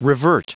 Prononciation du mot revert en anglais (fichier audio)
Prononciation du mot : revert